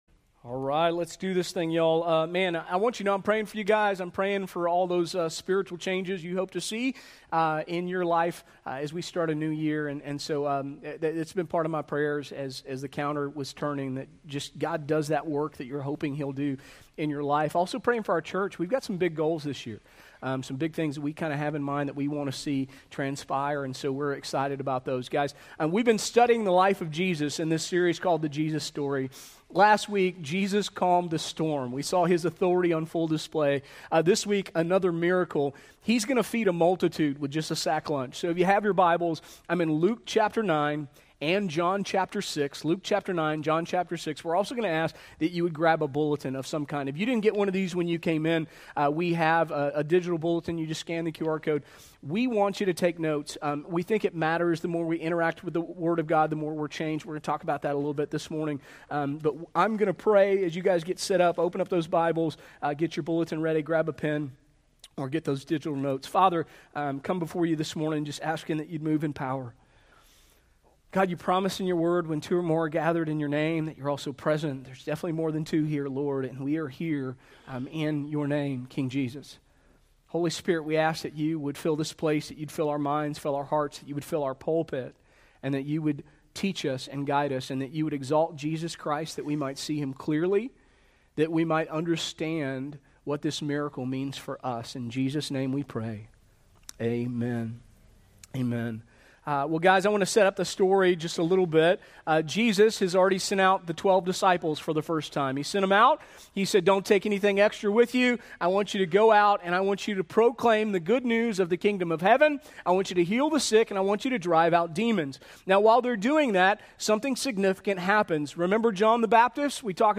1 Sermon 1.4.2026 - Songs of the King - The Song of the Multitude - Revelation 7:9-10